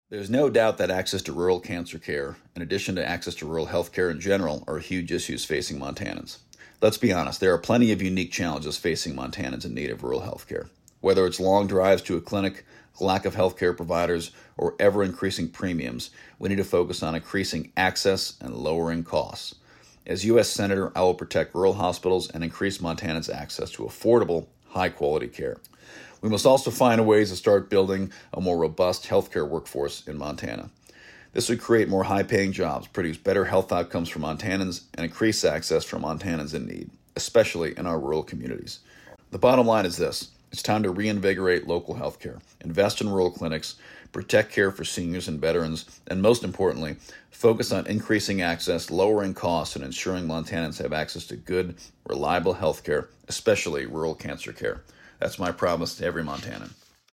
The candidates' responses were aired on Voices of Montana, October 9-11, 2024.